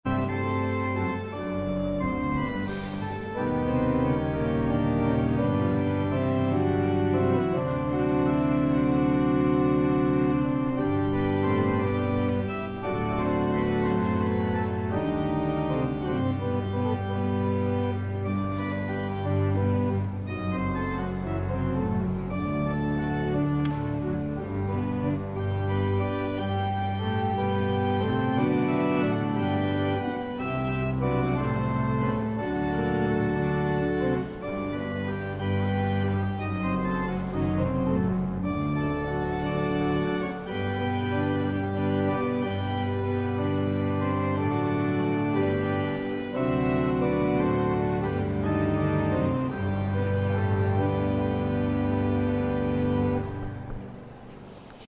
Hemen dituzu organoarekin eta dultzainarekin jotako obra batzuk.